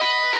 guitar_003.ogg